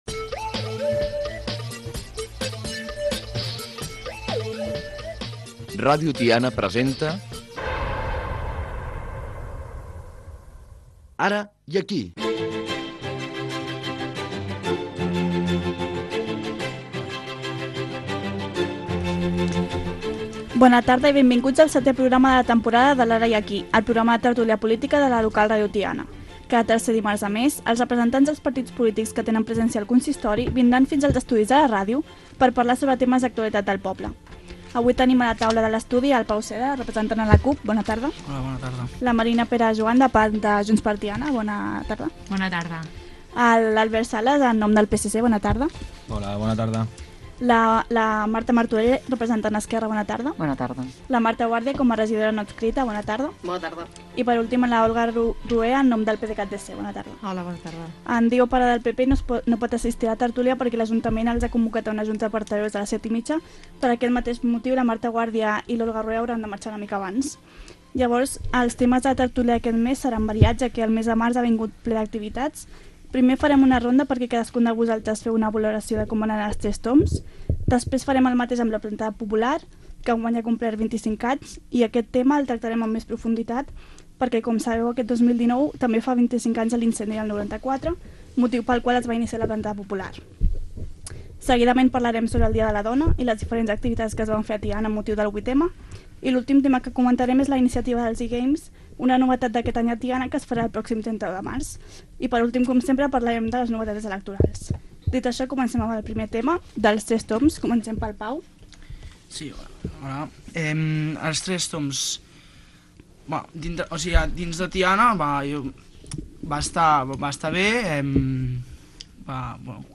Careta del programa. Tertúlia política amb representants dels partits CUP, Junts per Tiana, PSC, Esquerra, Pdcat.
Informatiu